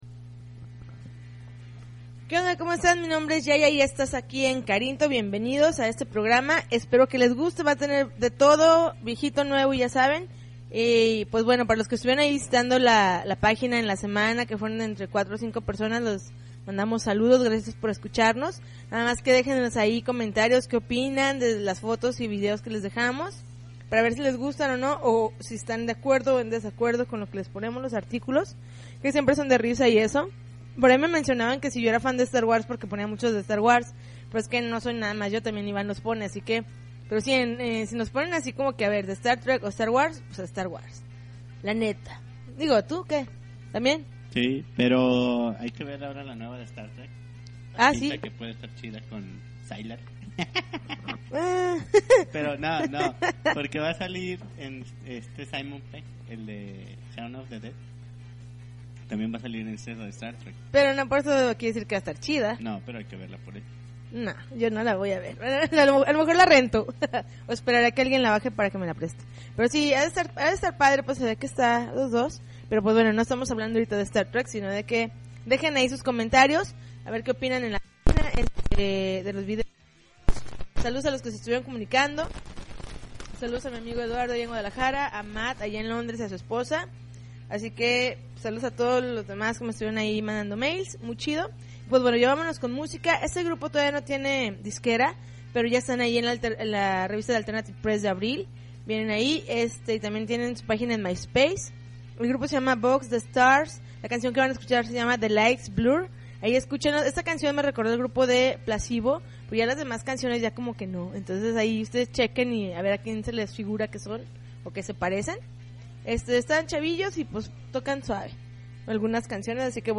April 12, 2009Podcast, Punk Rock Alternativo